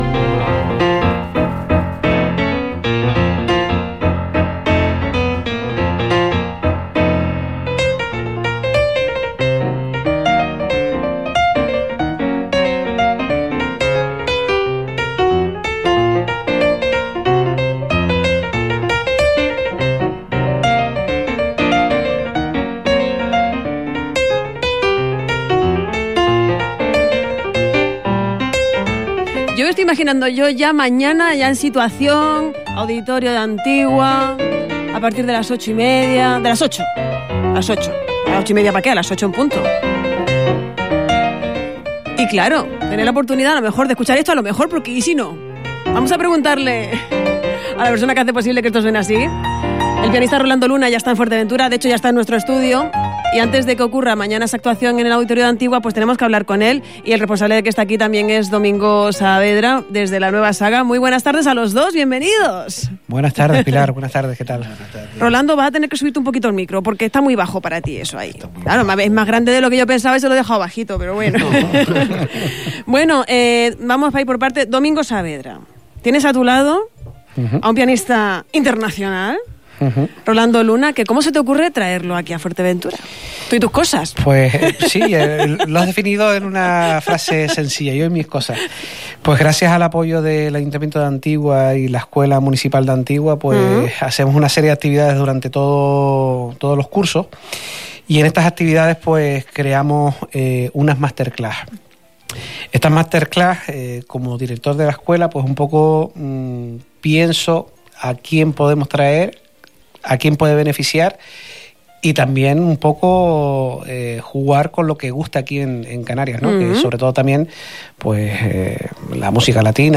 ha venido a Radio Insular Fuerteventura